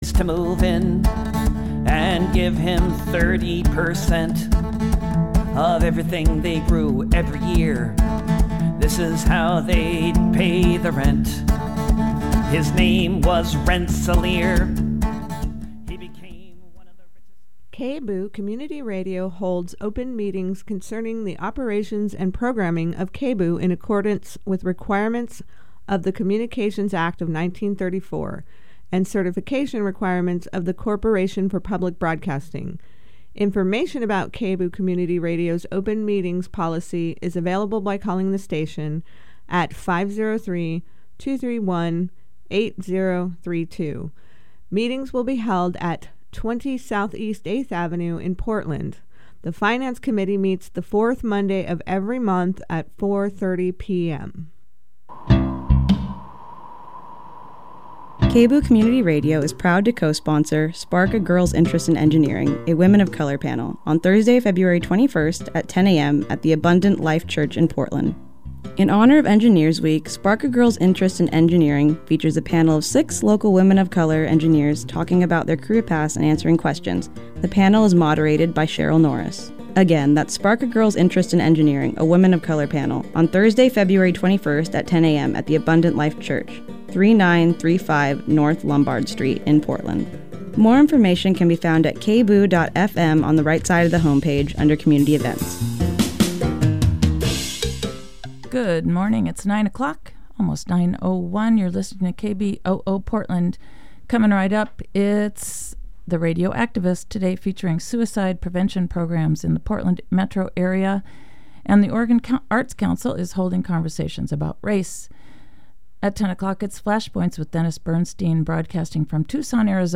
We’ll also hold a seat for new KBOO volunteers joining us in Studio 2 as they learn the ropes of media.